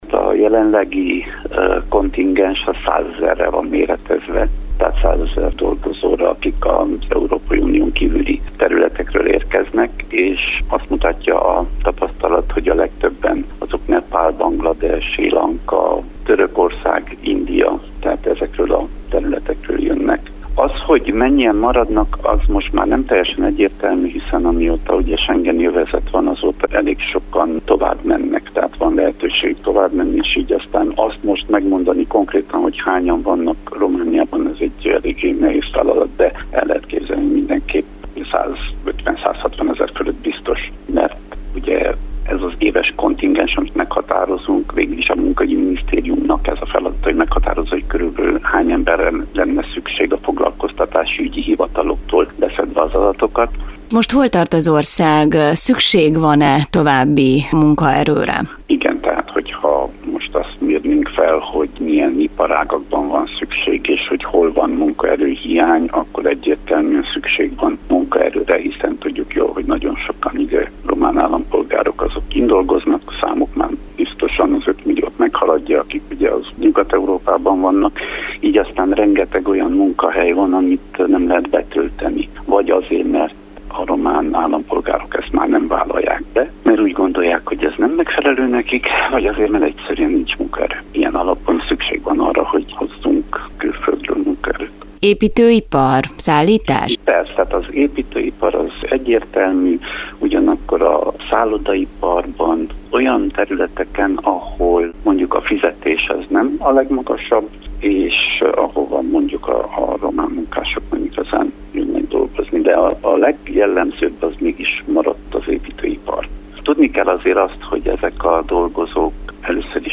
Derzsi Ákos munkaügyi államtitkárral arról is beszélgettünk, hogy milyen területen van szükség EU-n kívüli vendégmunkásokra.